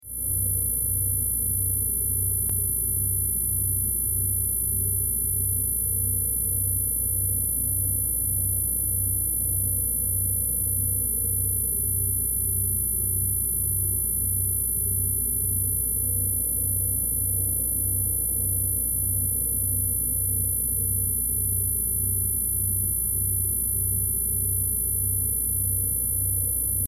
Frequency meditation for higher consciousness. sound effects free download